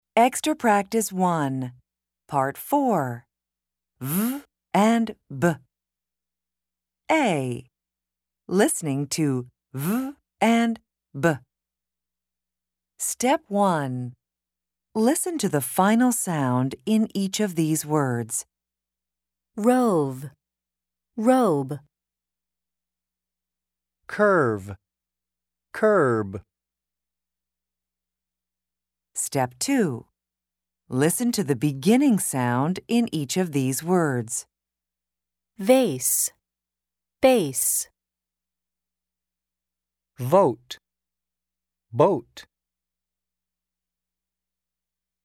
Pronunciation and Listening Comprehension in North American English
American English